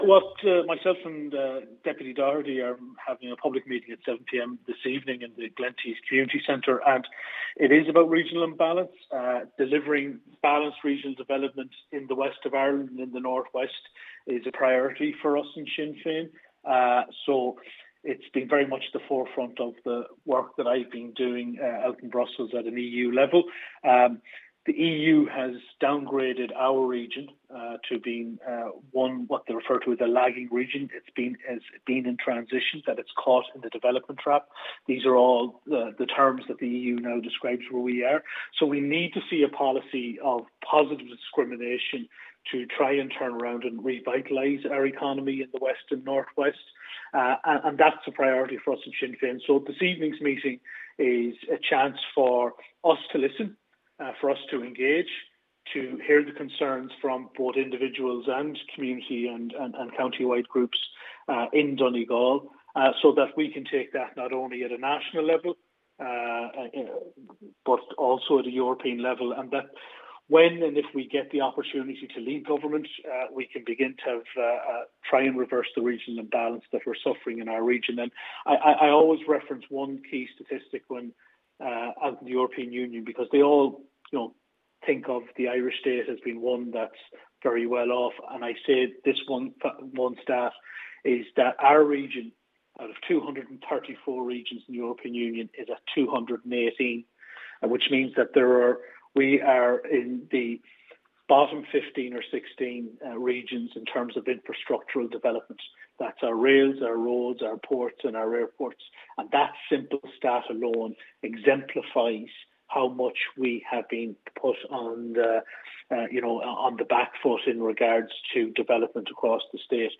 MEP MacManus says it is evident that a change in policy is needed: